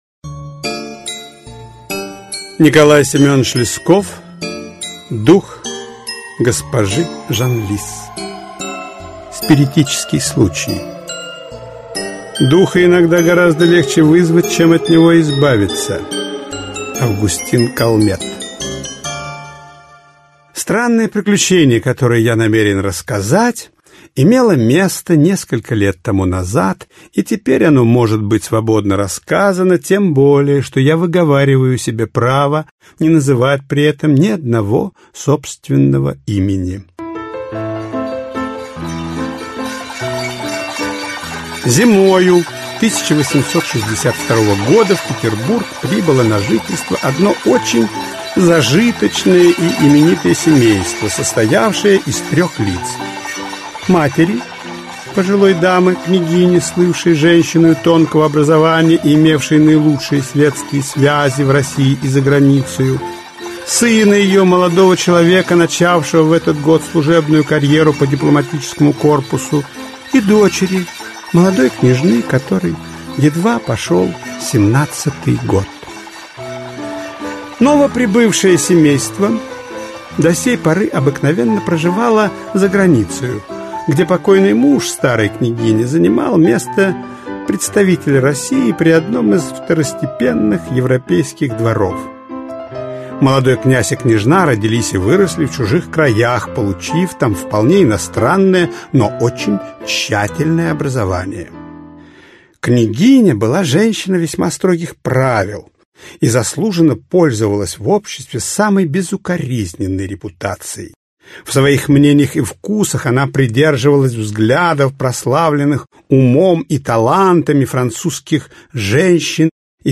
Аудиокнига Дух госпожи Жанлис. Аудиоспектакль | Библиотека аудиокниг
Аудиоспектакль Автор Николай Лесков Читает аудиокнигу Валерий Баринов.